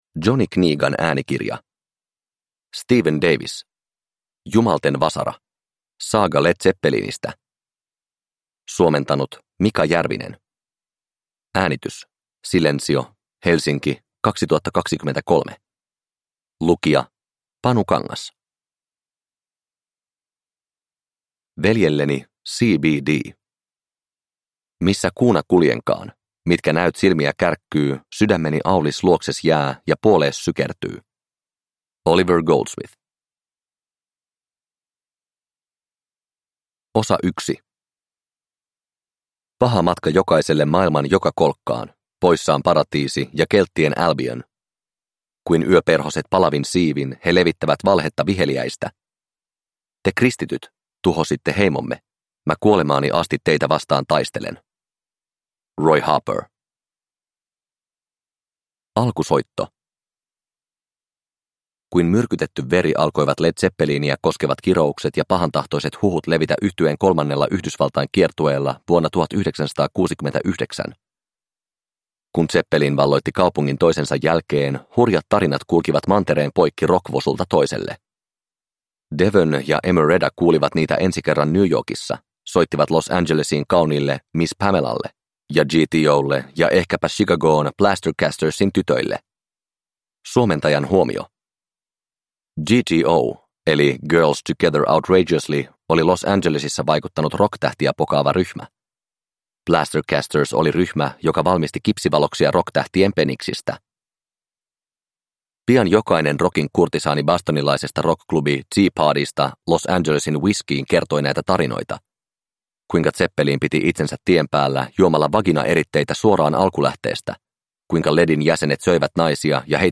Jumalten vasara – Ljudbok – Laddas ner